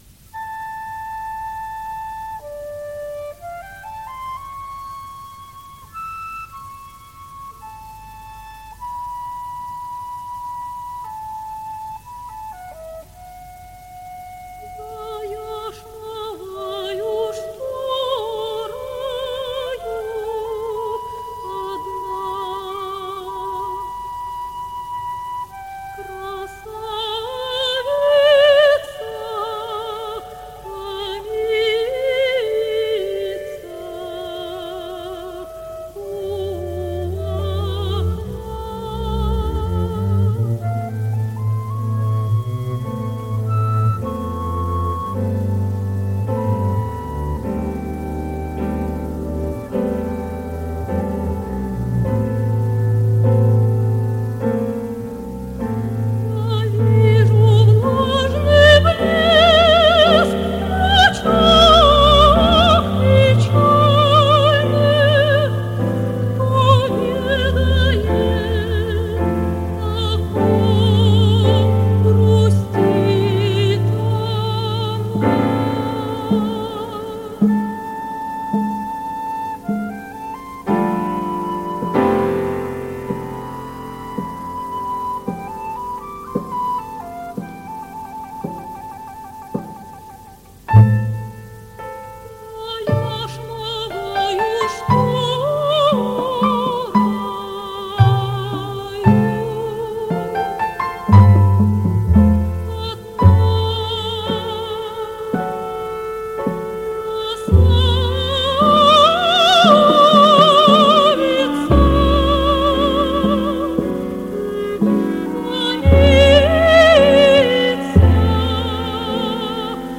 сопрано
флейта
кларнет
для вокально-инструментального ансамбля